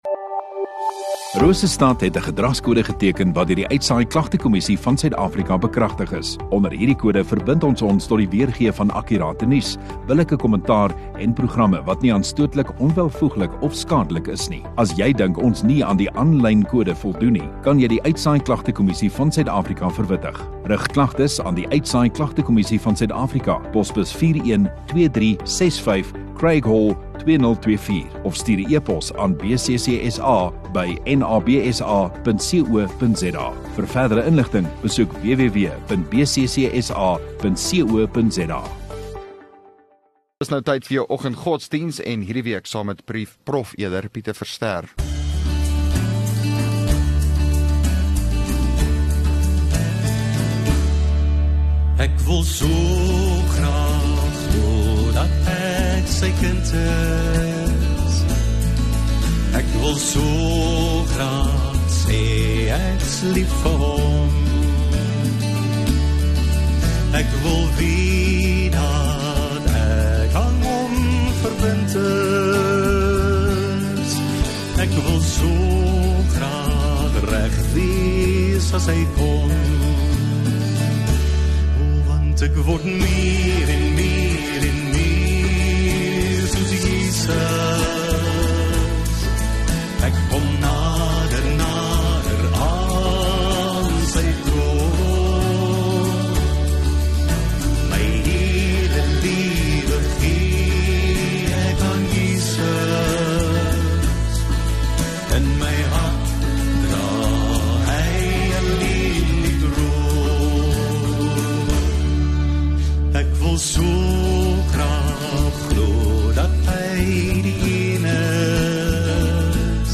29 Apr Dinsdag Oggenddiens